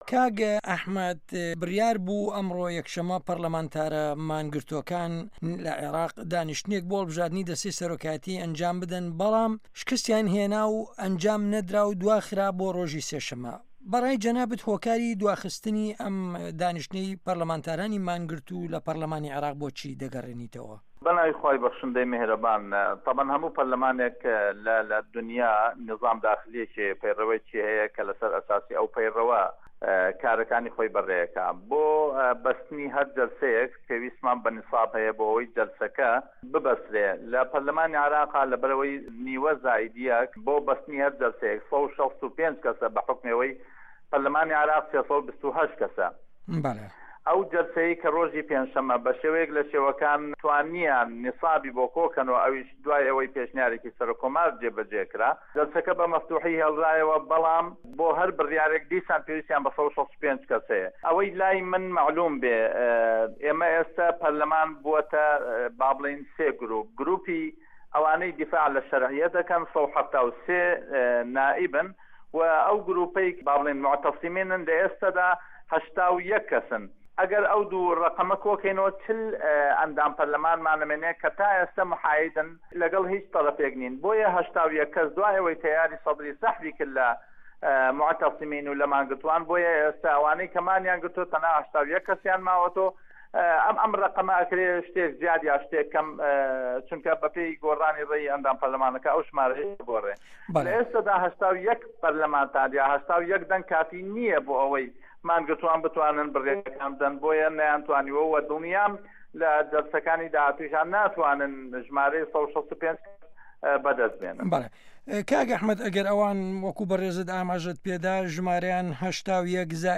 وتوێژ لەگەڵ ئەحمەدی حاجی رەشید